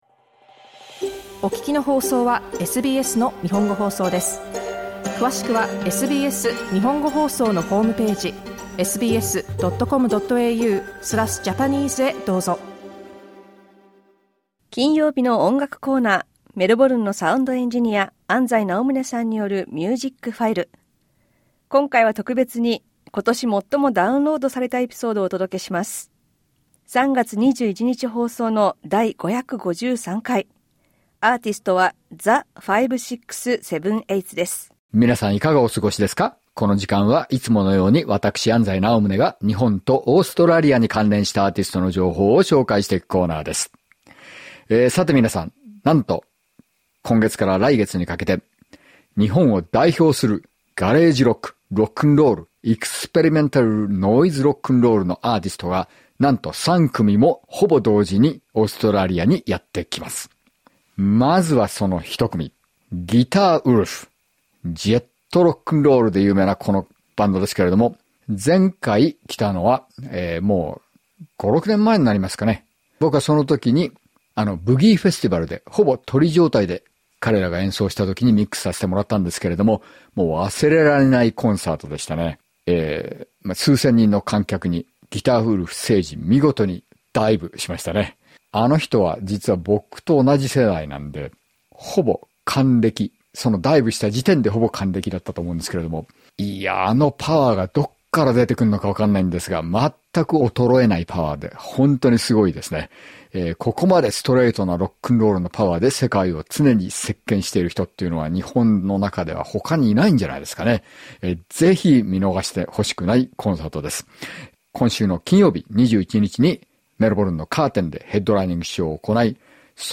The most downloaded episode in 2025 featured Japanese garage rock band The 5.6.7.8's, who were touring Australia at the time of the broadcast. Originally broadcast on 21 March 2025.